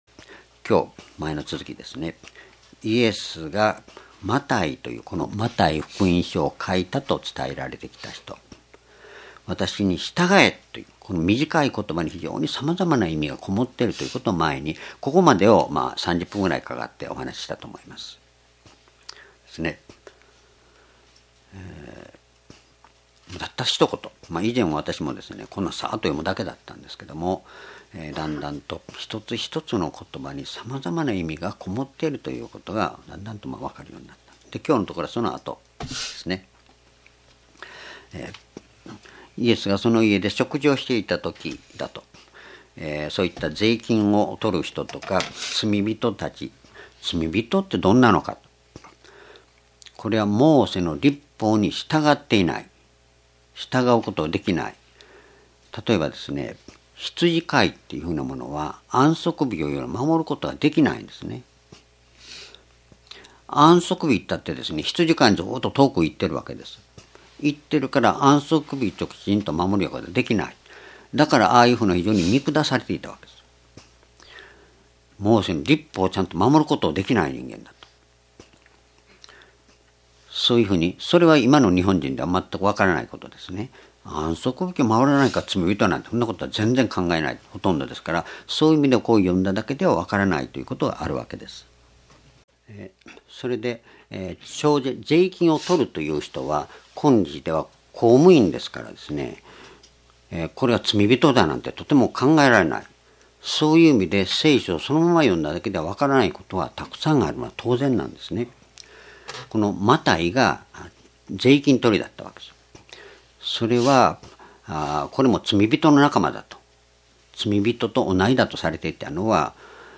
主日礼拝日時 2017年3月19日 主日 聖書講話箇所 マタイ福音書9章10節-13節 「私に従え」 ※視聴できない場合は をクリックしてください。